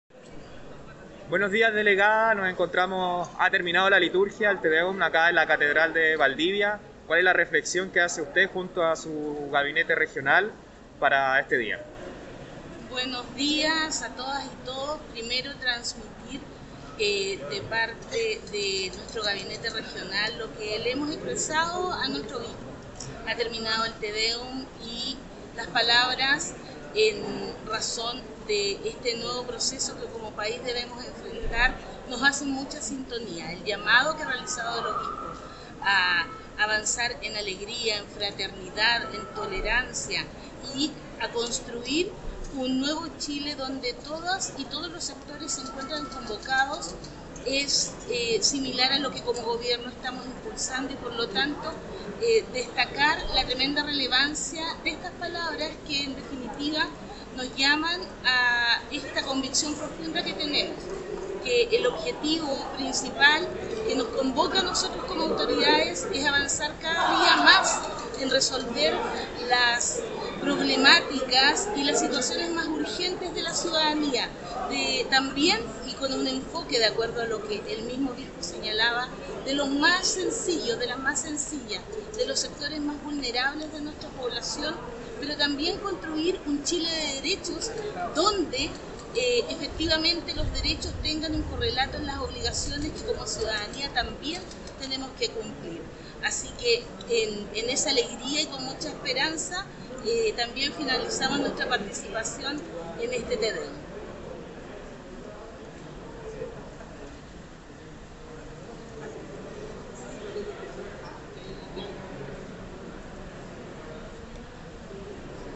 01-tedeum-catedral.mp3